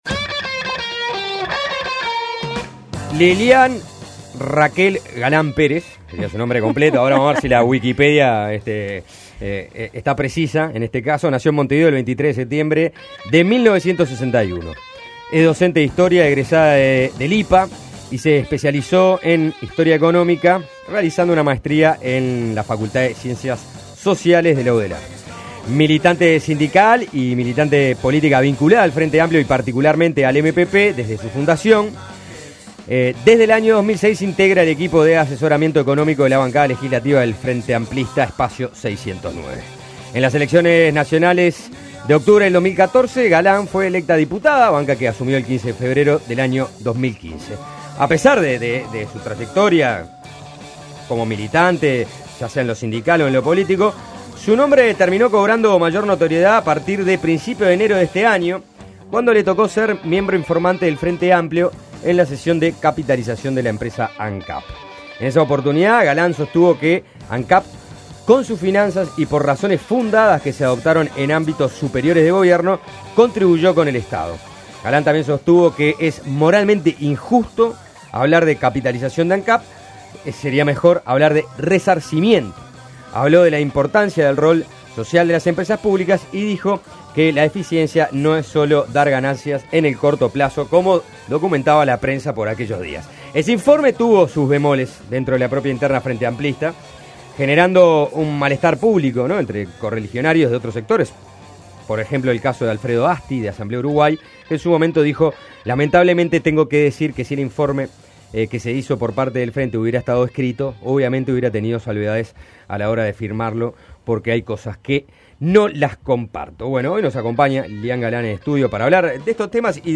Entrevistamos a la diputada del MPP, Lilián Galán, quien consideró moralmente injusto hablar de capitalización de Ancap y habló sobre la existencia de intereses que atentan contra la empresa en un momento de prospección de petróleo en el país.